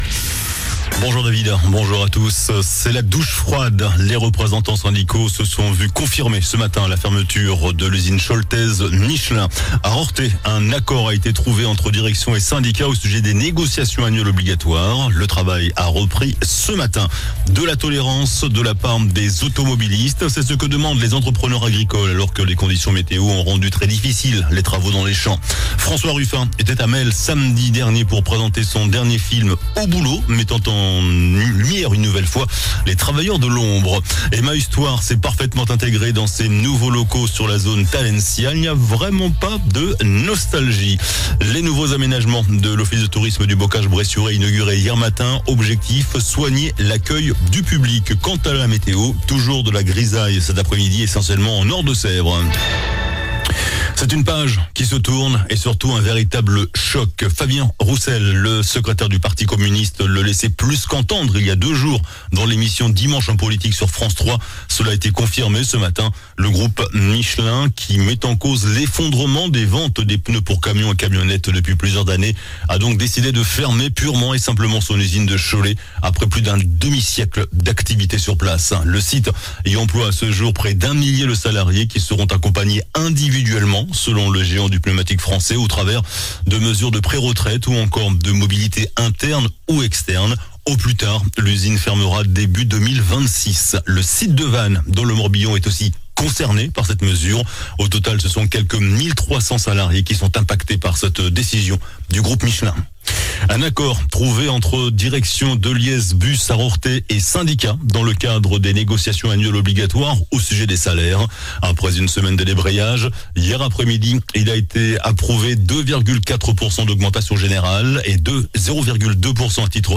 JOURNAL DU MARDI 05 NOVEMBRE ( MIDI )